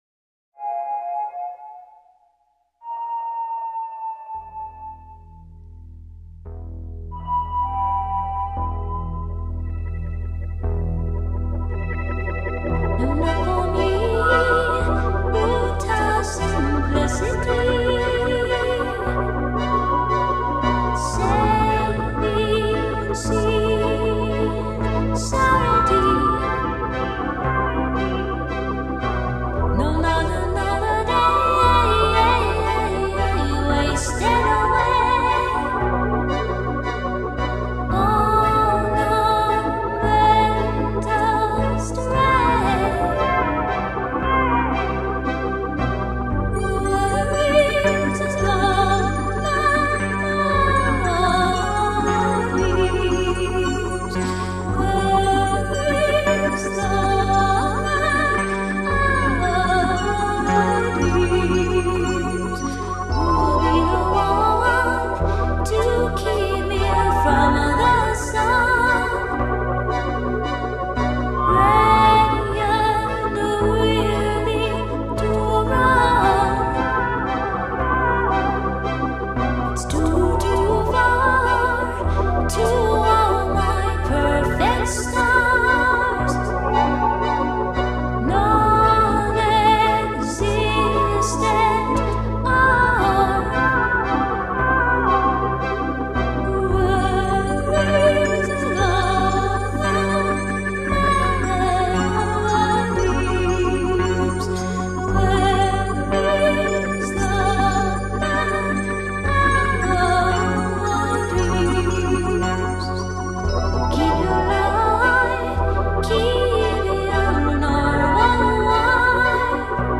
These are slow waltzes: